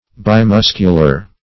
Search Result for " bimuscular" : The Collaborative International Dictionary of English v.0.48: Bimuscular \Bi*mus"cu*lar\, a. [Pref. bi- + muscular.]